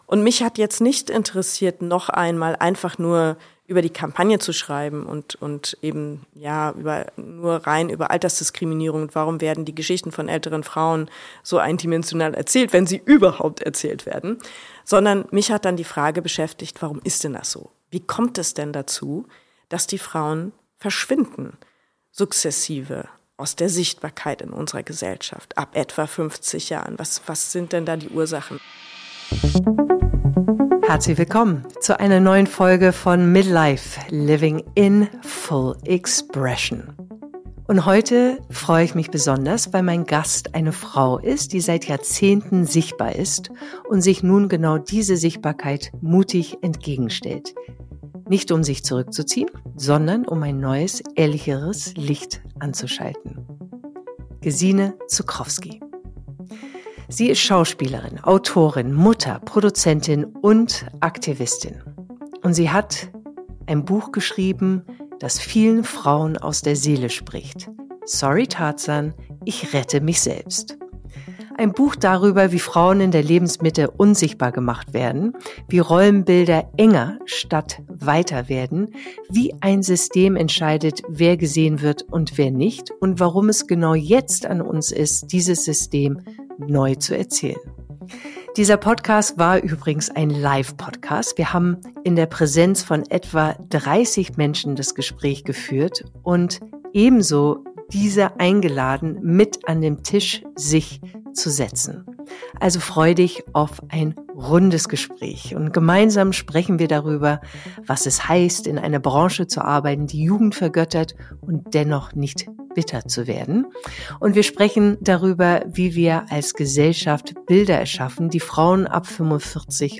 (Live-Podcast aus dem Zippelhaus, Hamburg)
Ein Gespräch über Mut, Wahrheit und das große Geschenk, sich selbst neu zu erzählen.